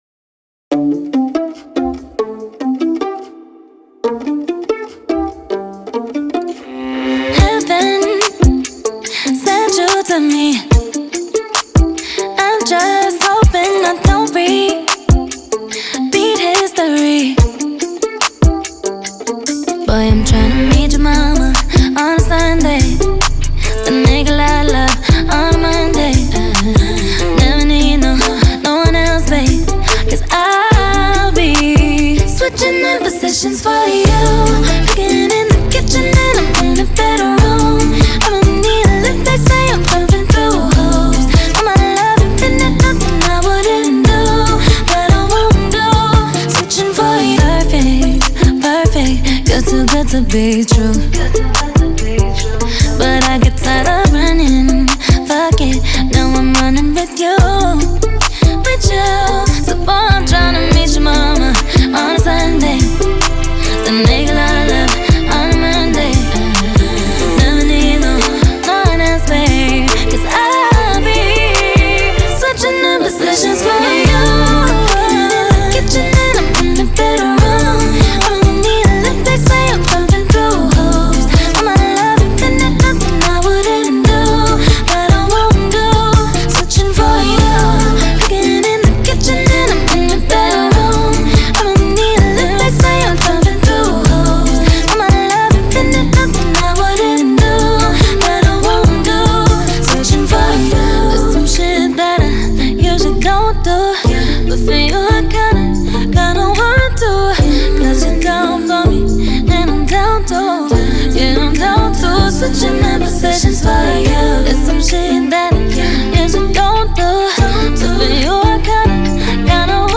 new single
Female singer